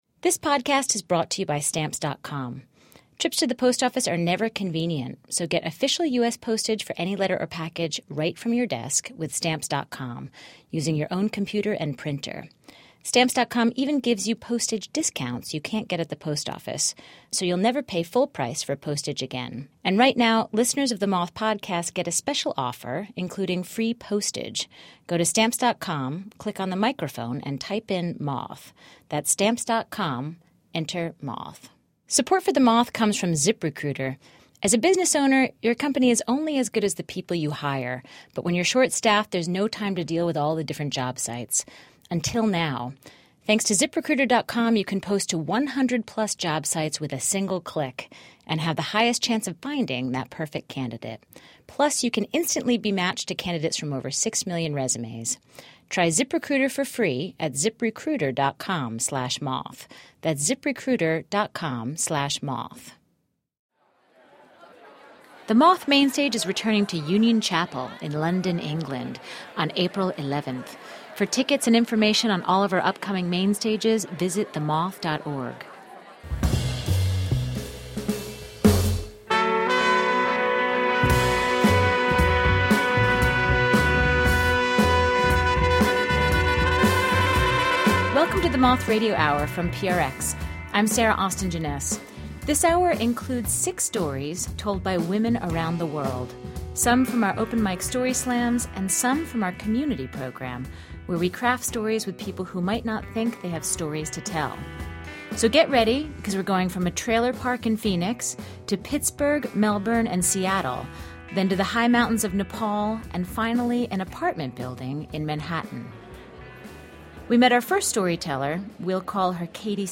A special episode featuring stories from women around the world. Resilient children, computer crashes, swimming lessons, life after a house fire, standing up to bullies and accepting help from strangers.